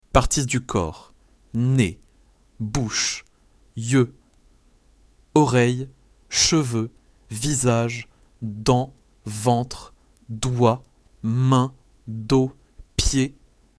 Lesson 3